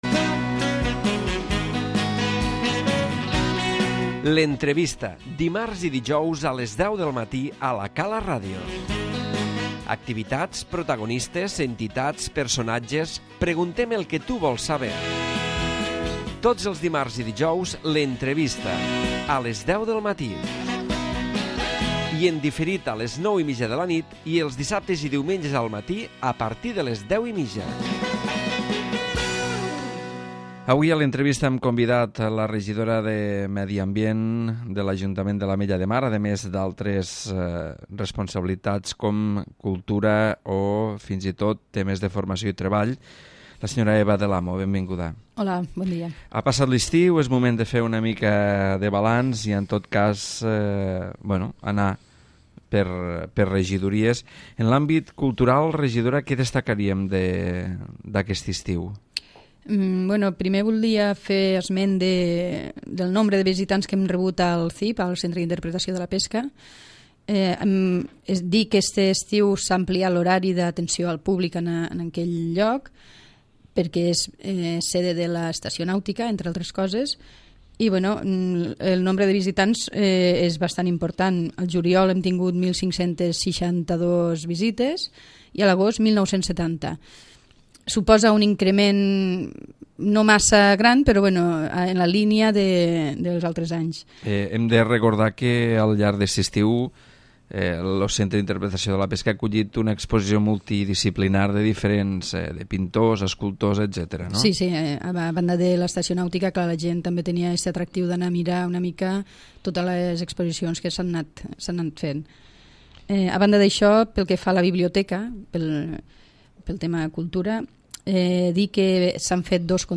L'Entrevista Eva del Amo
Avui a l'Entrevista, Eva del Amo, regidora de Medi Ambient, Cultura i Formació i Treball, ens fa un balanç, per regidories, d'aquest estiu.